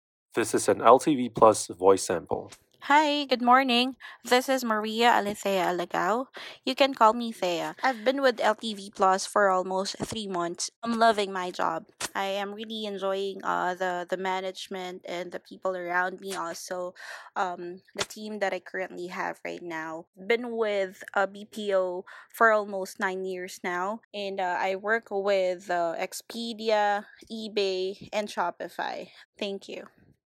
female call agent icon
Voice sample 8